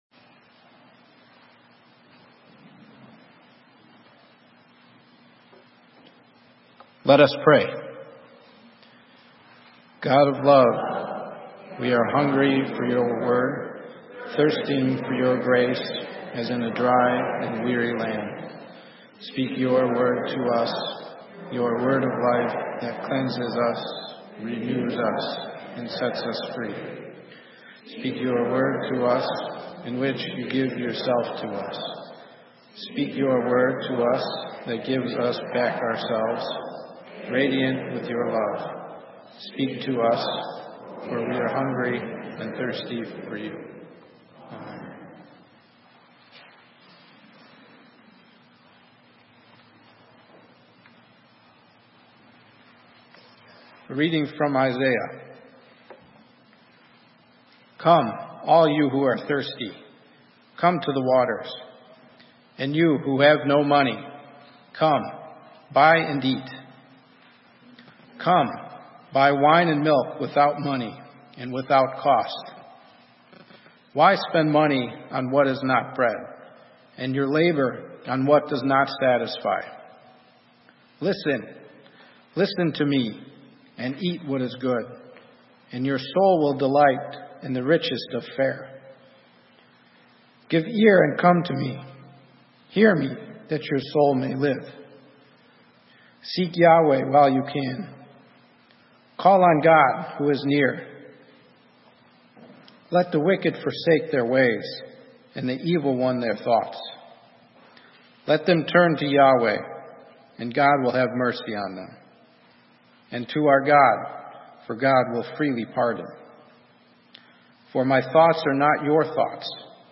Sermon: Nourishing Repentance - St. Matthew's UMC